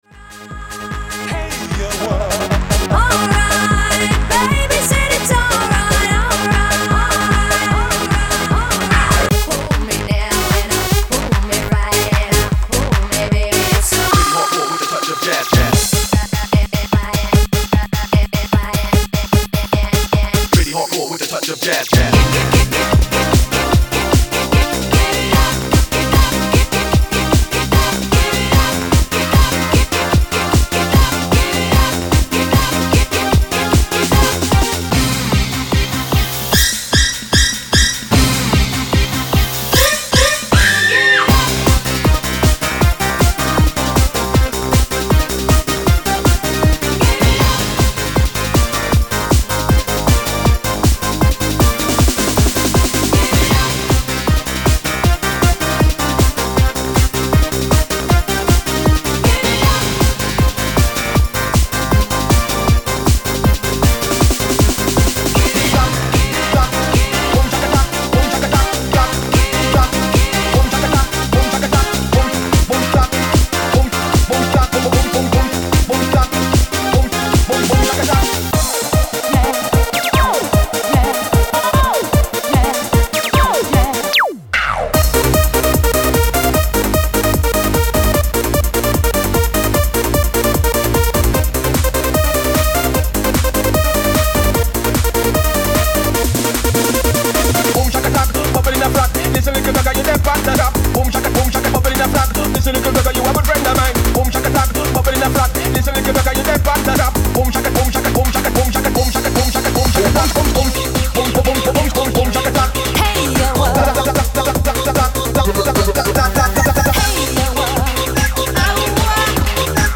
Жанр: Electronica-Trance